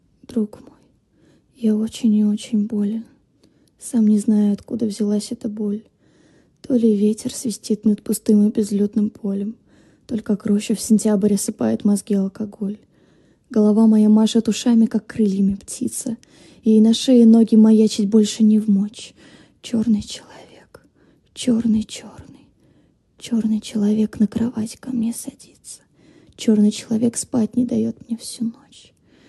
Жен, Аудиокнига/Средний
Мои демо были записаны на самые разные устройства, чтобы вы могли ознакомиться со звучанием моего голоса.